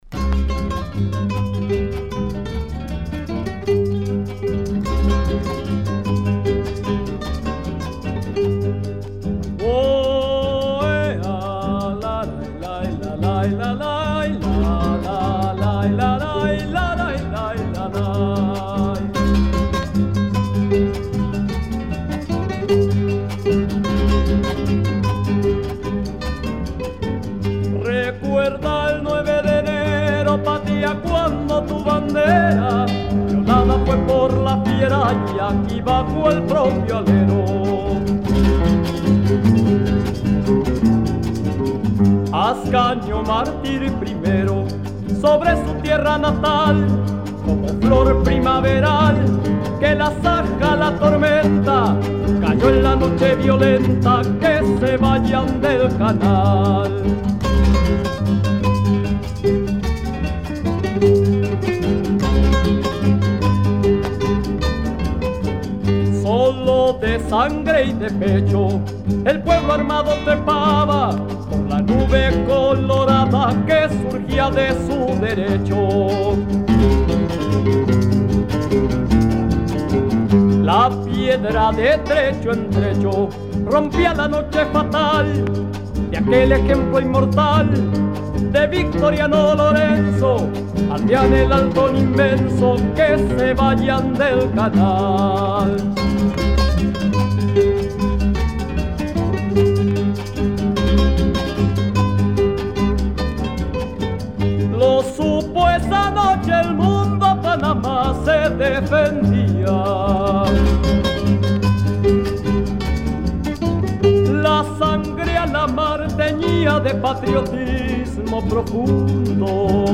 Оцифровал.